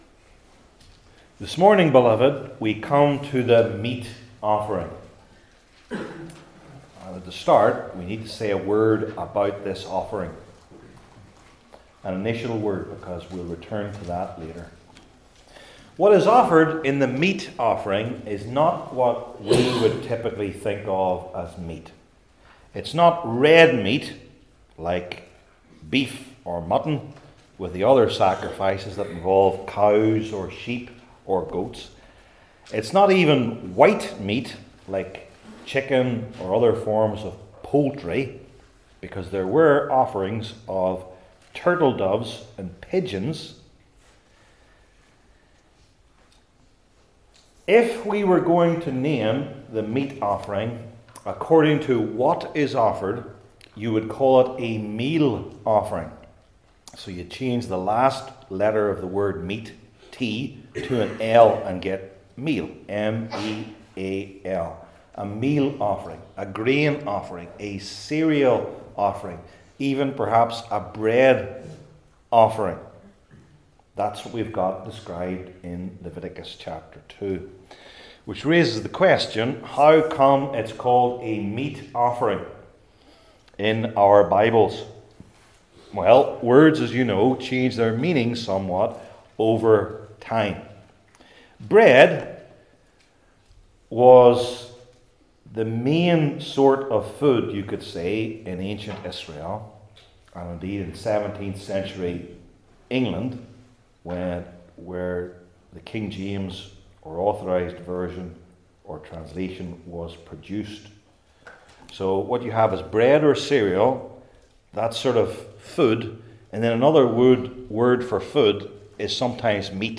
Old Testament Sermon Series I. The Central Symbolism II.